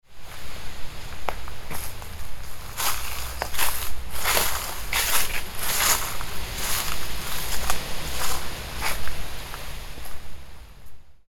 Footsteps On Dry Leaves & Strong Wind – Realistic Autumn Sound Effect
Experience the crisp sound of footsteps on dry autumn leaves while a powerful wind blows in the background.
Ideal for Foley, cinematic projects, background ambience, and windy outdoor scenes.
Genres: Sound Effects
Footsteps-on-dry-leaves-and-strong-wind-realistic-autumn-sound-effect.mp3